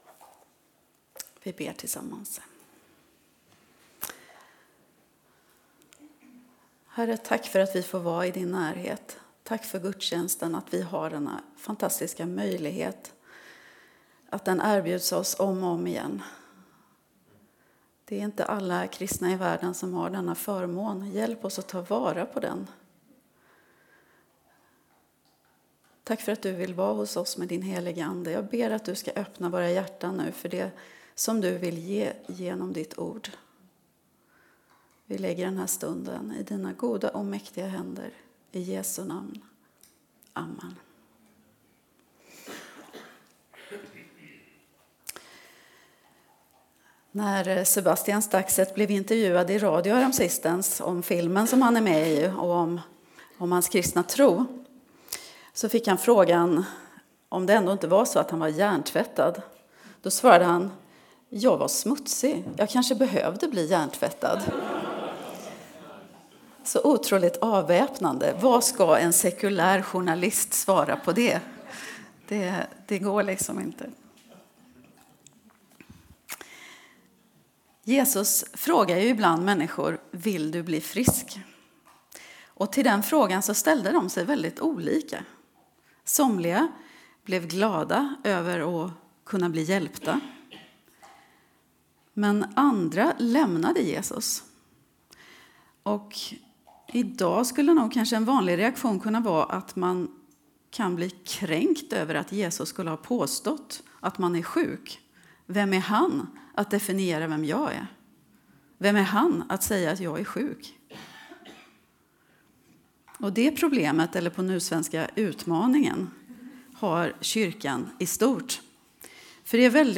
Öjersjökyrkan